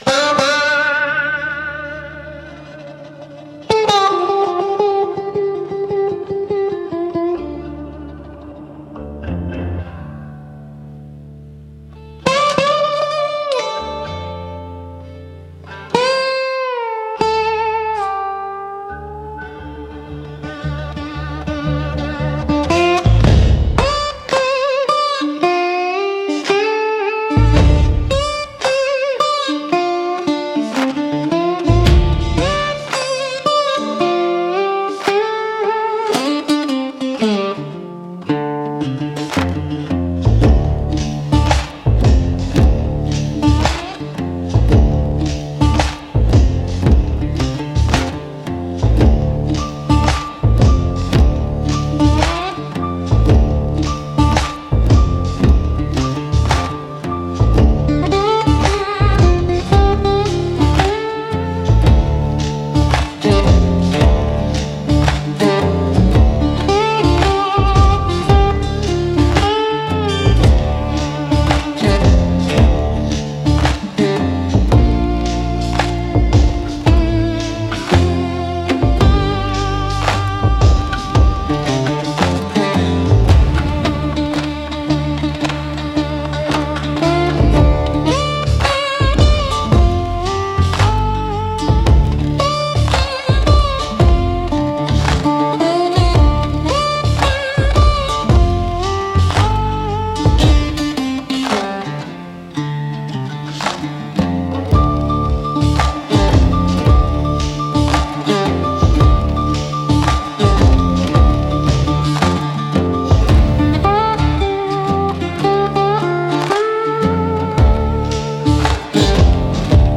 Instrumental - Ain't No Grave Deep Enough 3.11